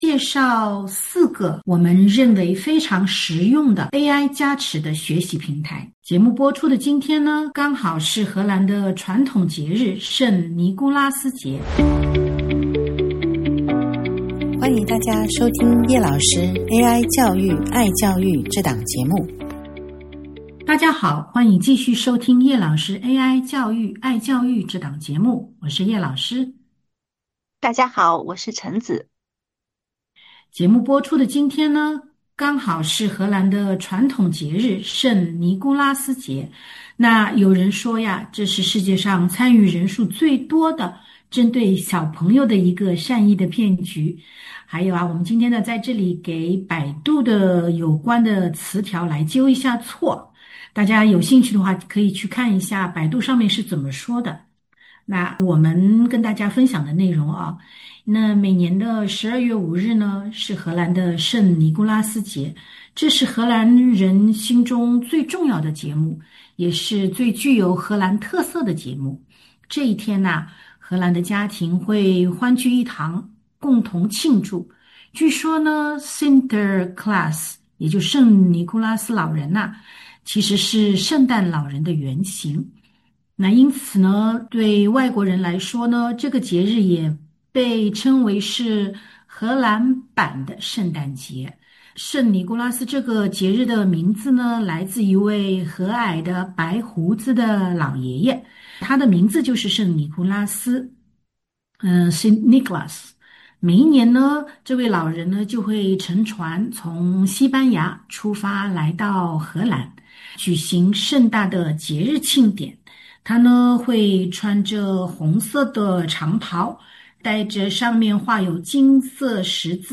本期节目，两位主持人以荷兰的传统节日圣尼古拉斯节介绍作为开头，向大家介绍了四个AI加持的学习平台：